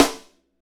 Index of /90_sSampleCDs/Northstar - Drumscapes Roland/DRM_Funk/SNR_Funk Snaresx
SNR FNK S0FR.wav